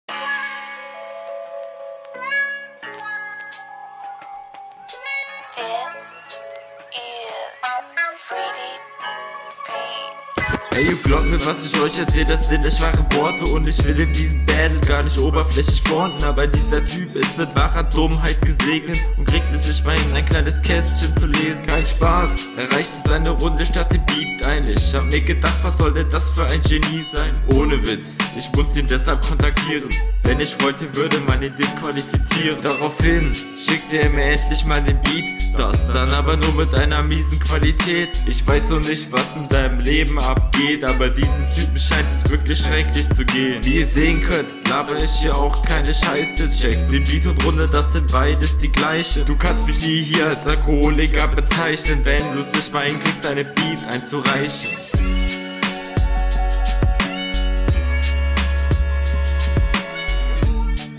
fands leider flowlich schwächer bist leider nicht wirklich auf den front eingegangen find langweilig zu …